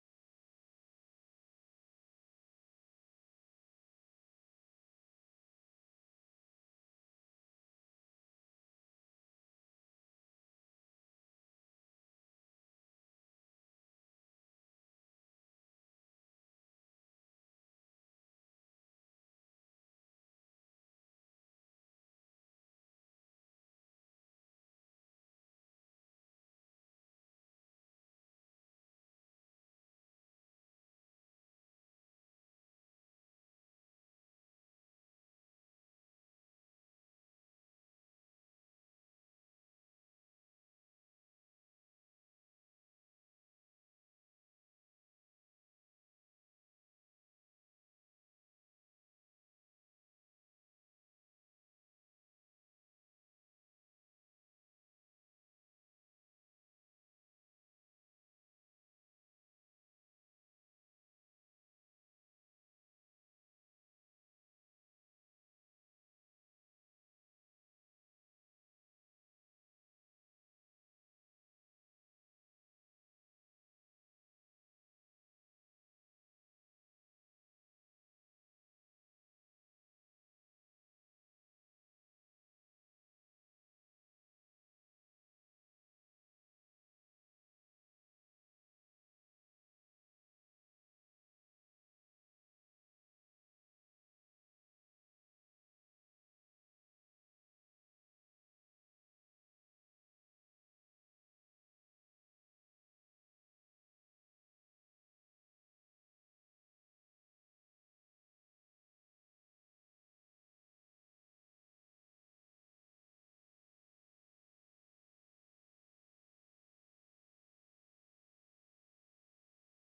Functie: Presentator